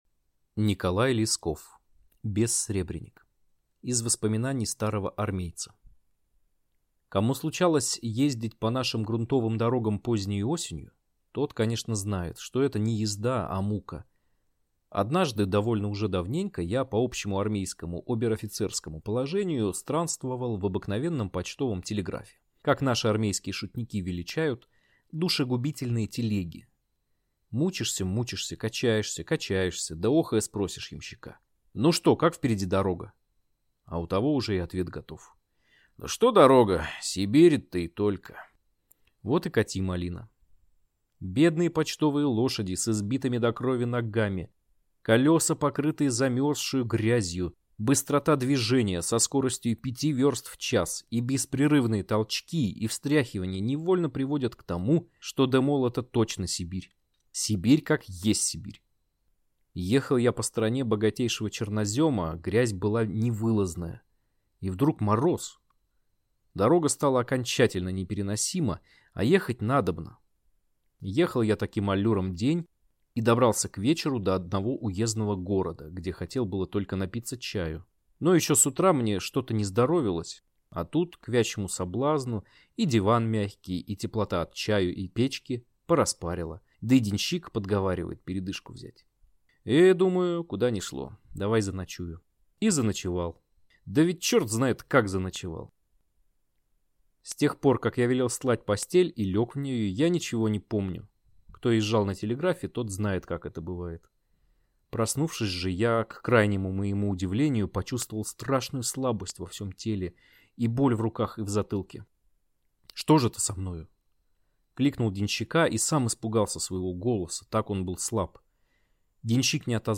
Аудиокнига Бессребреник | Библиотека аудиокниг